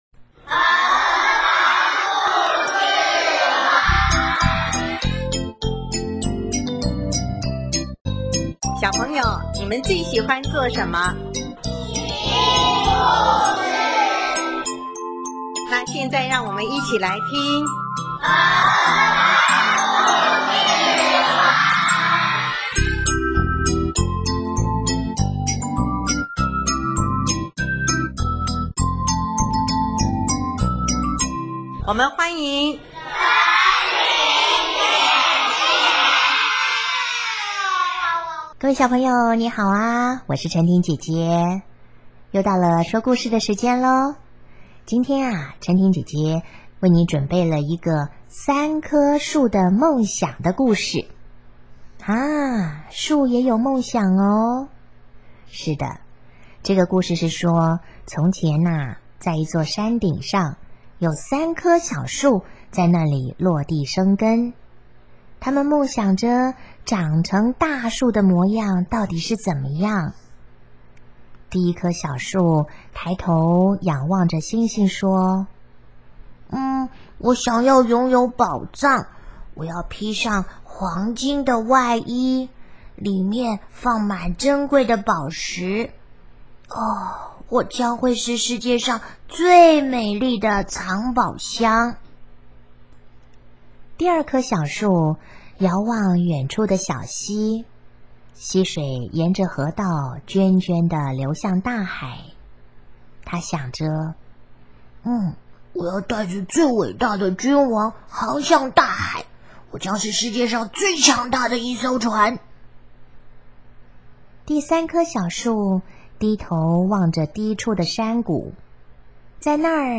首页 / 家庭/ 儿童故事